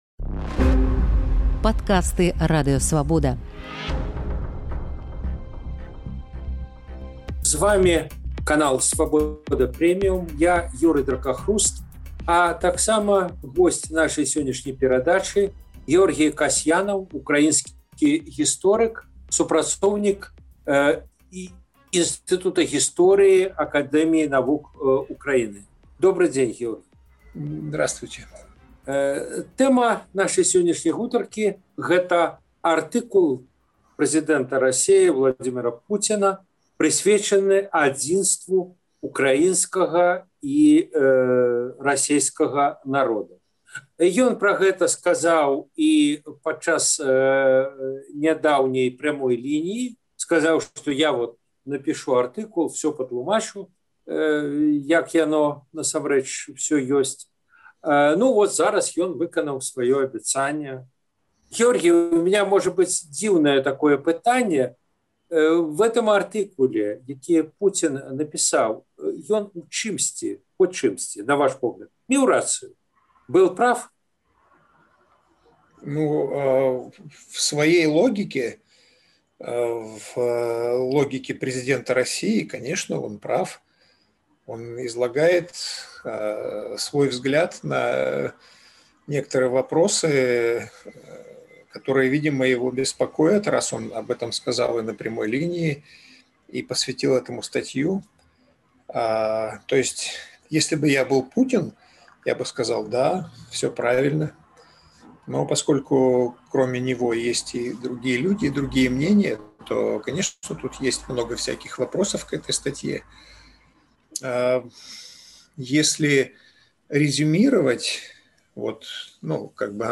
У чым мае і ў чым ня мае рацыю Пуцін у сваім артыкуле пра адзін народ – расейцаў, украінцаў і беларусаў? Якія палітычныя высновы вынікаюць з артыкулу прэзыдэнта Расеі? Адказвае ўкраінскі гісторык